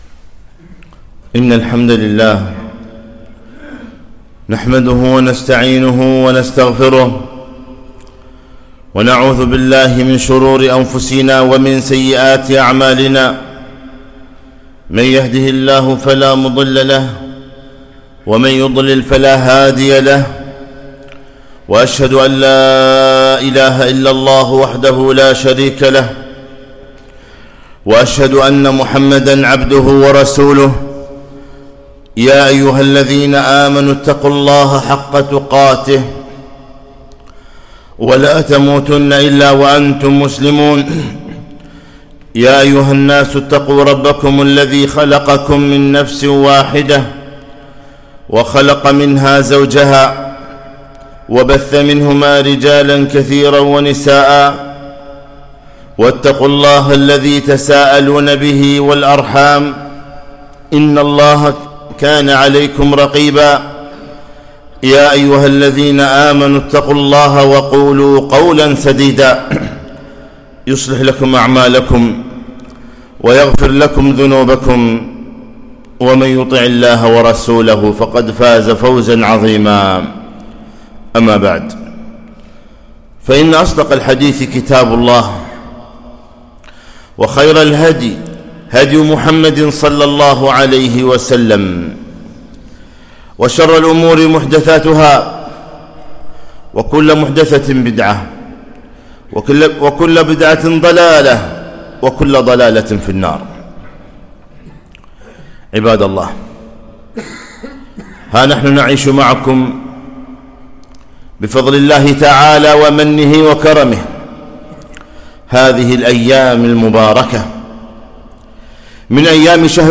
خطبة - حافظ على حسناتك في رمضان